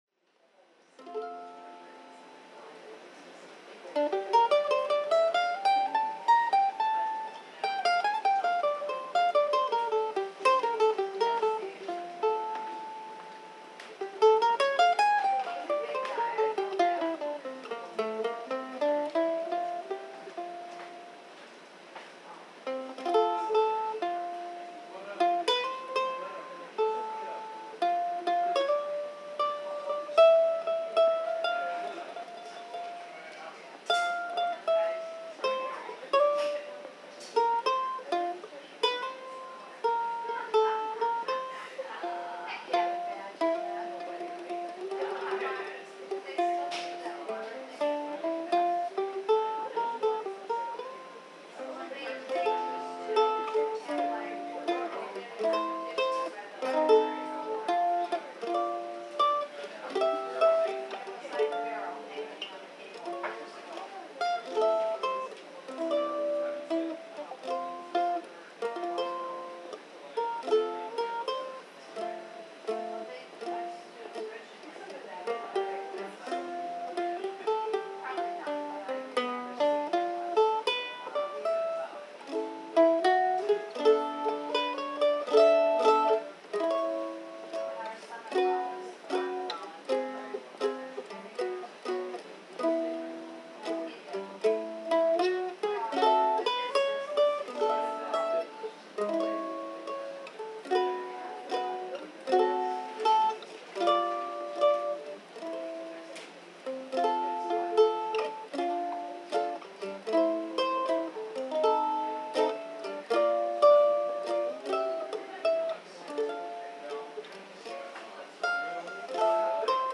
mp3) (live from JJ's)